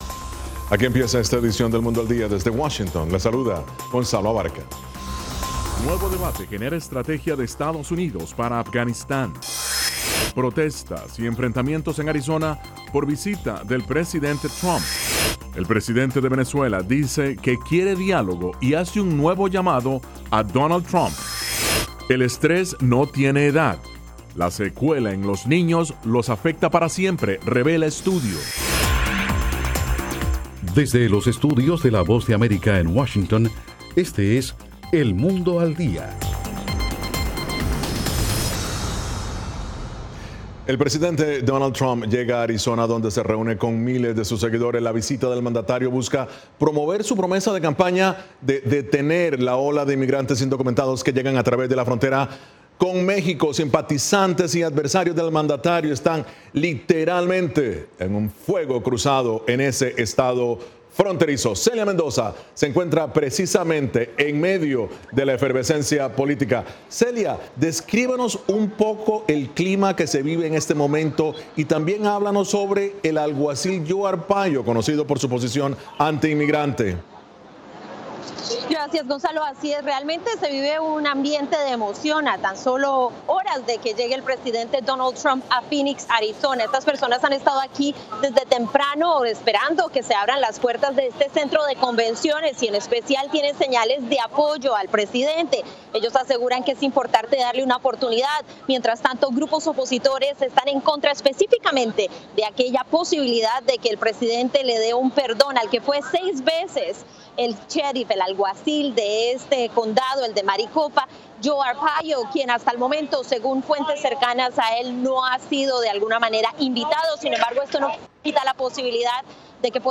Las noticias del acontecer de Estados Unidos y el mundo